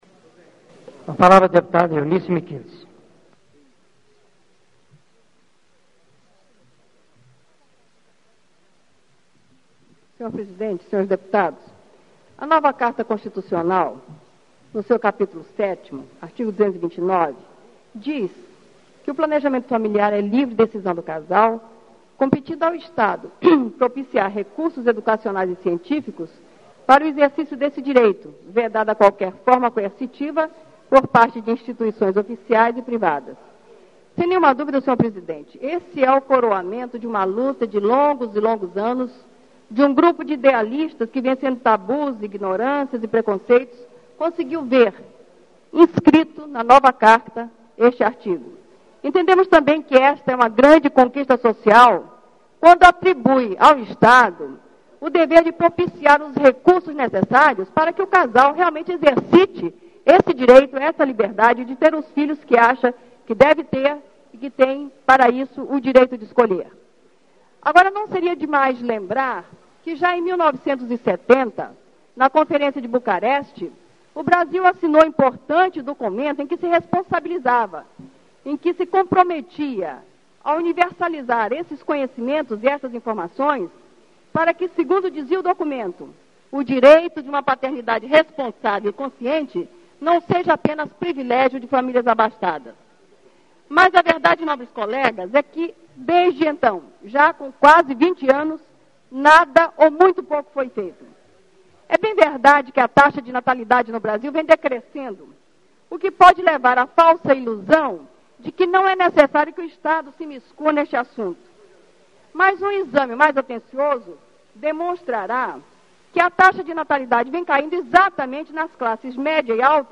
- Discurso pronunciado em 09 de agosto de 1988 – Bancada EvangélicaAcusações contra a atuação da bancada evangélica na Assembleia Constituinte, veiculadas pelo Jornal do Brasil e pelo Correio Braziliense; denúncia de troca de votos por favores.